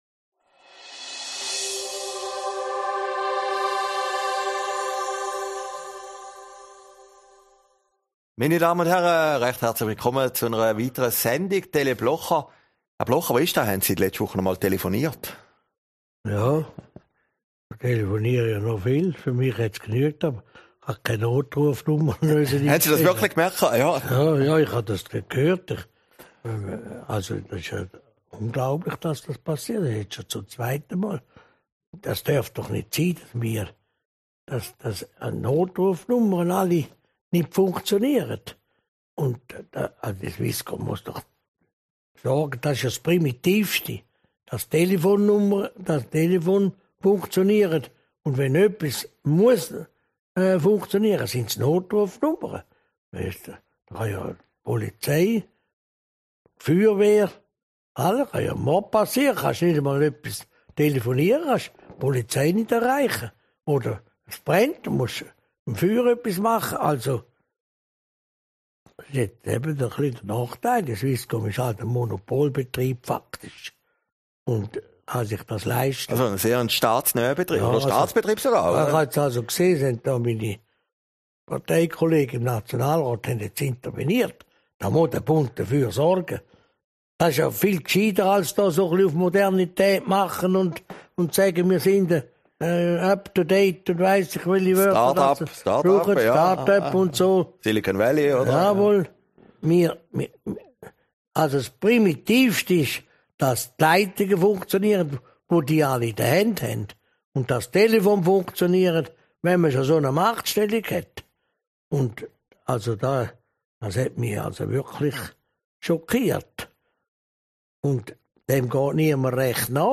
Aufgezeichnet in Herrliberg, 21. Februar 2020